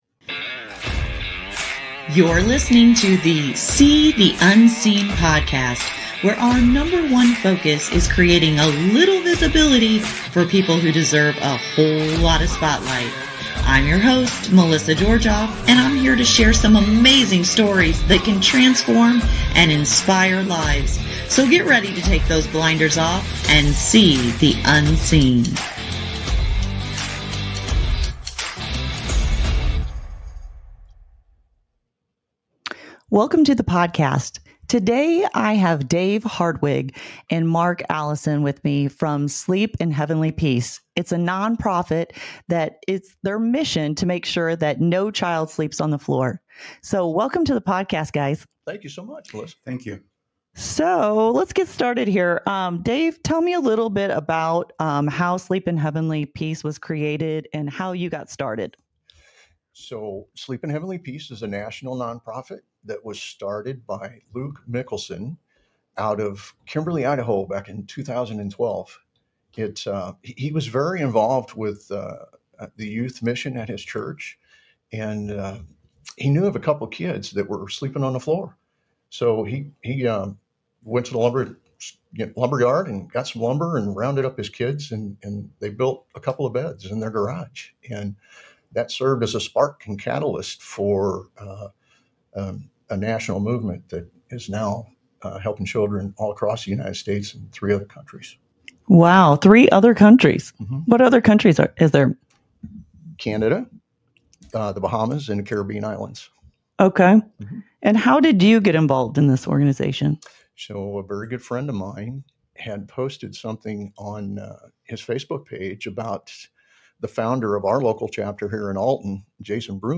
Be a guest on this podcast Language: en Genres: Music , Music Interviews Contact email: Get it Feed URL: Get it iTunes ID: Get it Get all podcast data Listen Now...